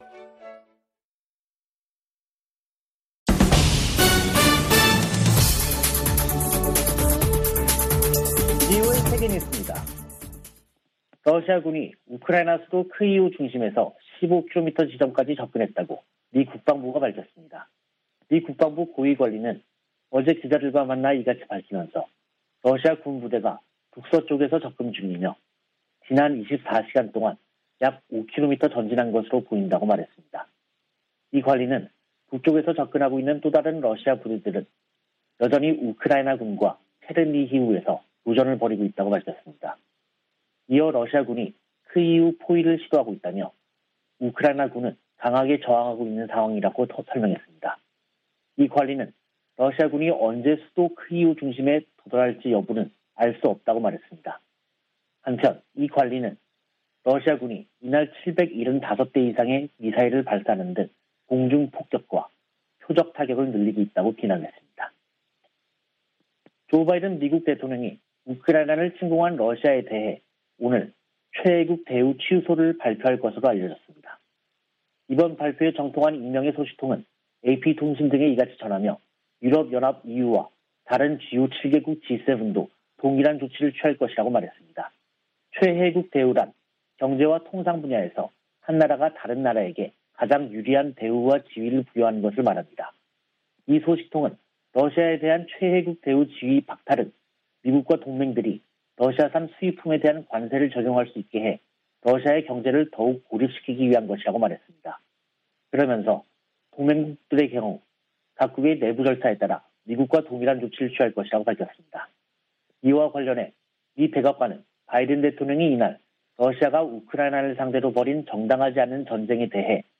VOA 한국어 간판 뉴스 프로그램 '뉴스 투데이', 2022년 3월 11일 2부 방송입니다. 미 국무부는 북한 핵·미사일 위협을 한국의 윤석열 차기 정부와 핵심 협력 사안으로 꼽았습니다. 미국 주요 언론은 한국에 보수 정부가 들어서면 대북정책, 미한동맹, 대중국 정책 등에서 큰 변화가 있을 것으로 전망했습니다. 북한이 폭파했던 풍계리 핵실험장을 복구하고 금강산에 있는 한국 측 시설을 철거하는 움직임이 포착된 것으로 알려졌습니다.